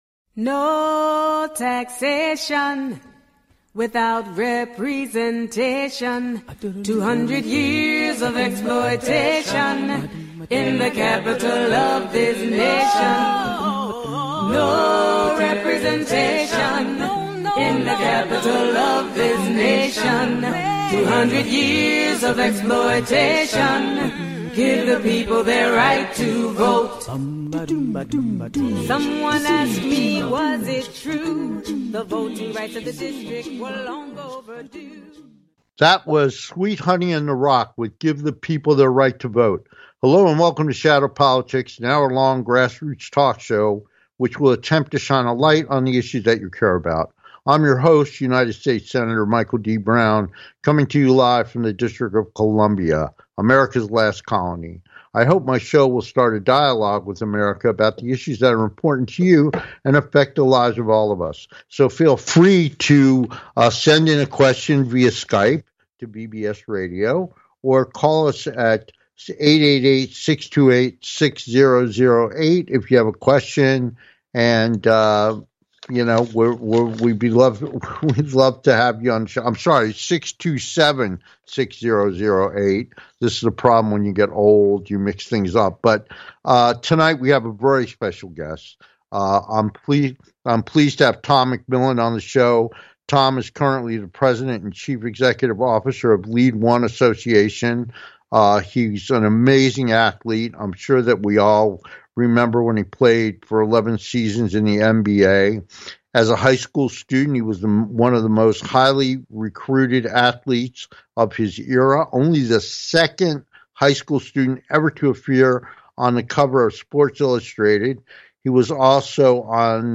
With Guest, Legendary Athlete, Businessman, congressman - Tom McMillen
Our guest is Legendary Athlete, Businessman, congressman - Tom McMillen - I'm excited to have a conversation with sports legend and old friend, Congressman Tom McMillen.